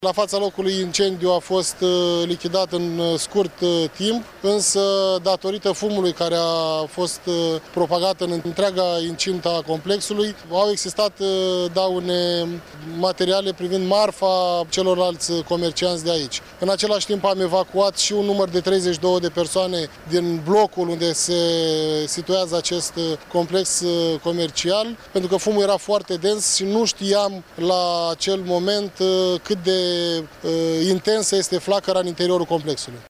O anchetă va stabili ce s-a întamplat şi la cât se ridică pierderile, a declarat prefectul de Vaslui, Eduard Popica.